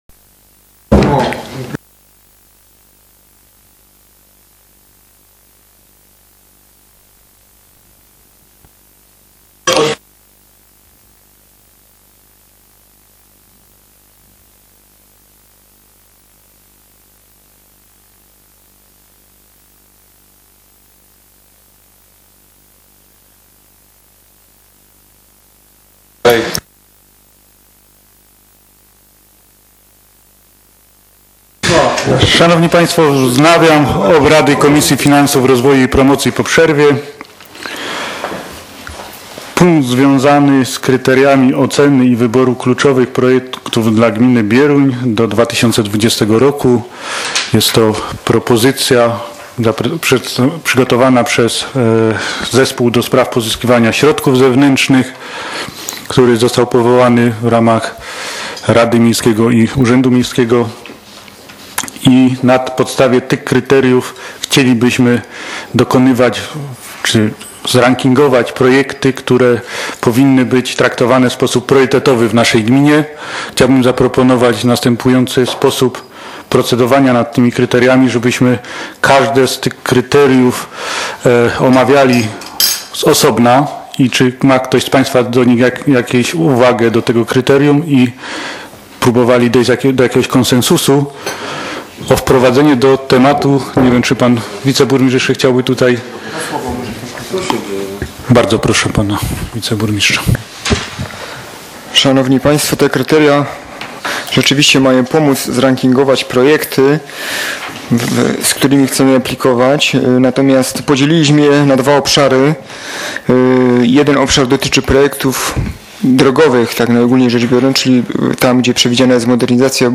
z posiedzenia Komisji Finansów, Rozwoju i Promocji w dniu 21.01.2016 r.